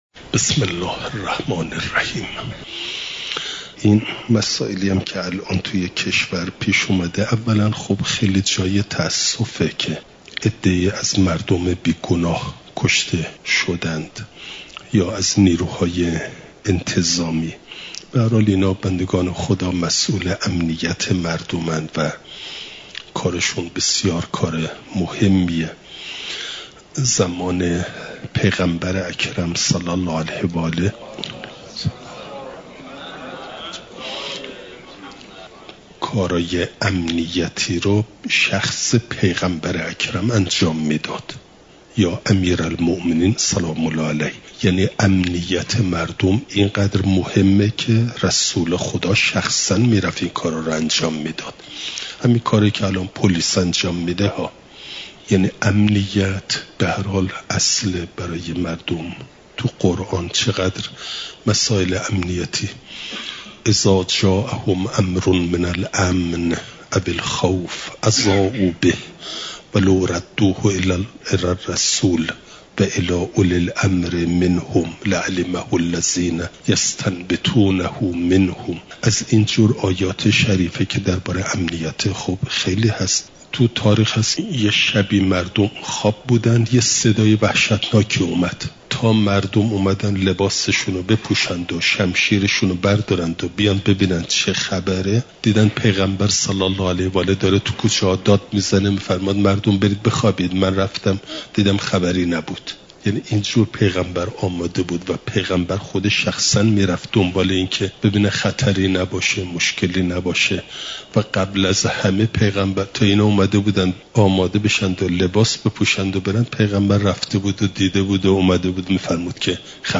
شنبه ۲۰دیماه ۱۴۰۴، حرم مطهر حضرت معصومه سلام ﷲ علیها